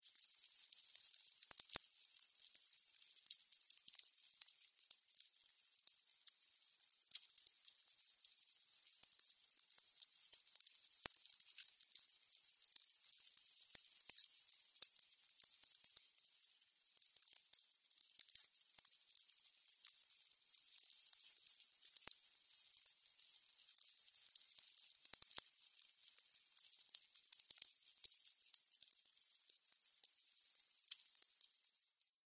Звуки горения свечей
Звук горящей свечи